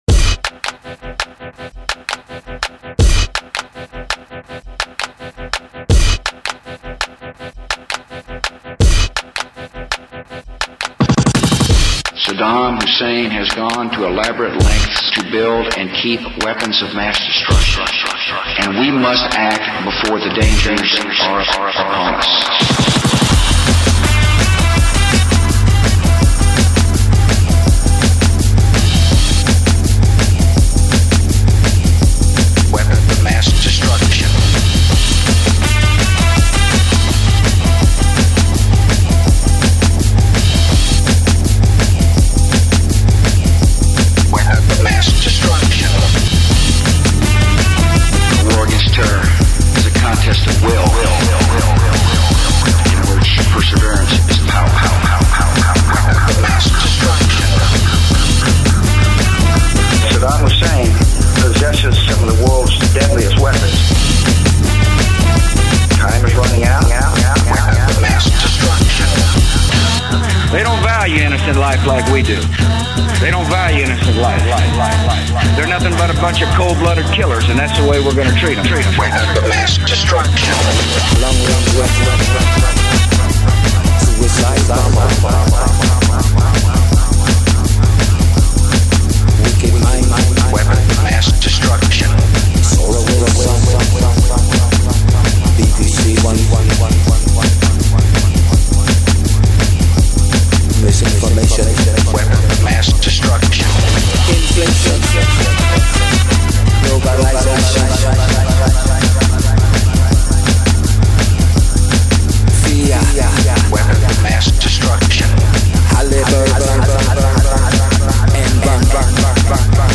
More of a mashup than cut-up, but still dead-on target.